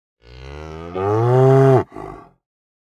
cow_moo2.ogg